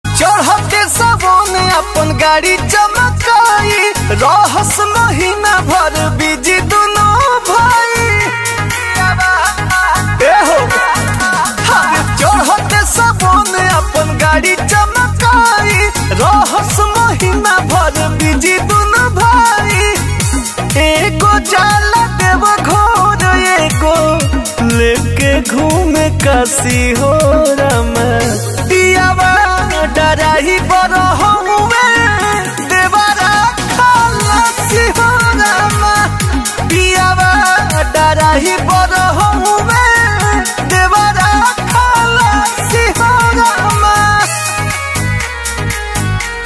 Bhojpuri Bolbum Ringtones